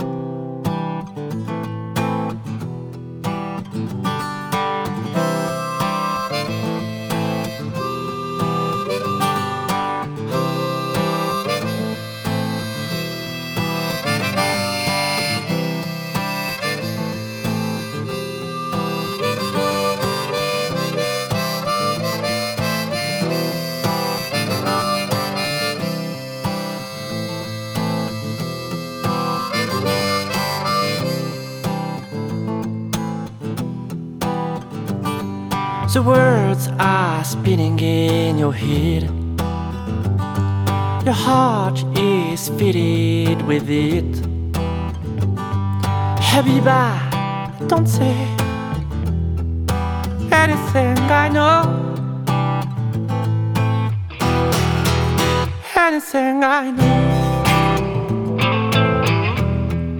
Entre folk intimiste, accents soul et éclats rock
Guitariste passionné